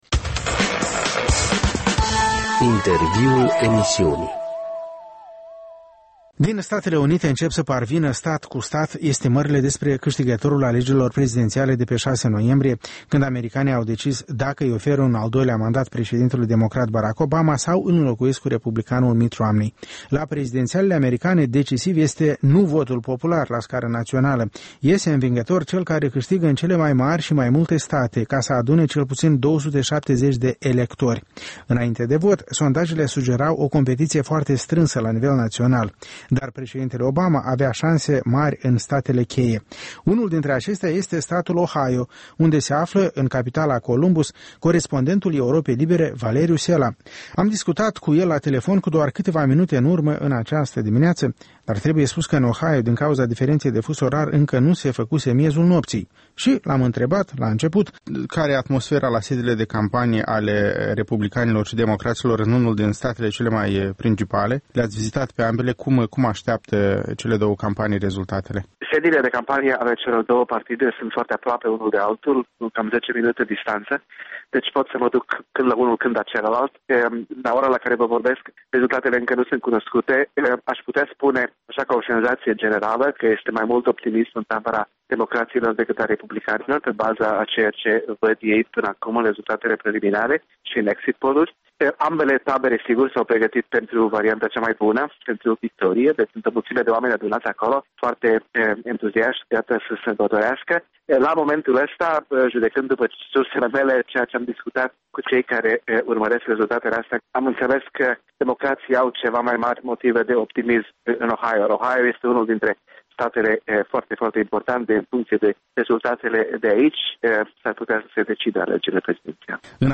O corespondență în direct de la Columbus, Ohio.